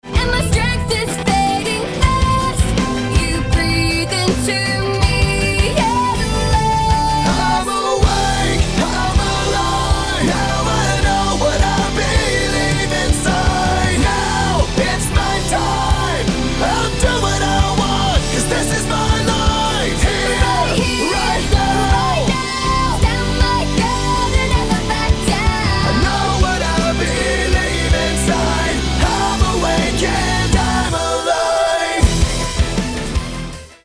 kresťanskej rockovej